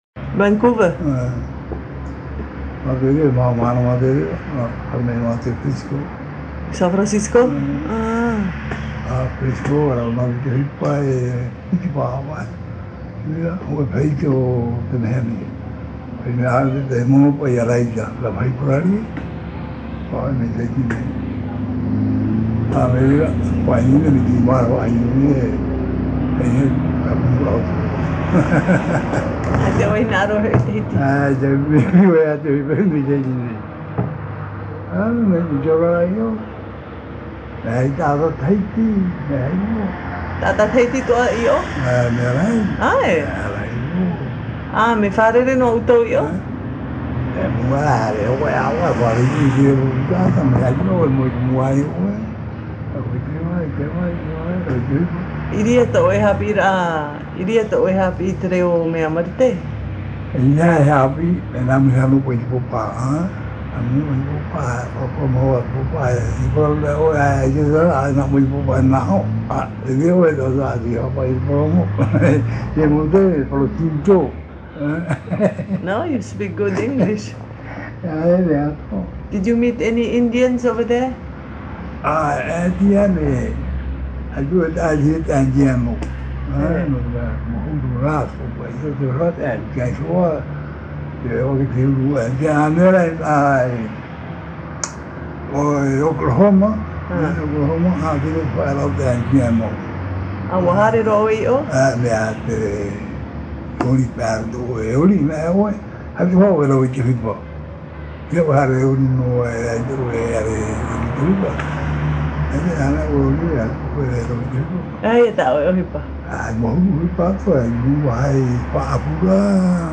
Interview réalisée à Pape'ete sur l’île de Tahiti.
Papa mātāmua / Support original : cassette audio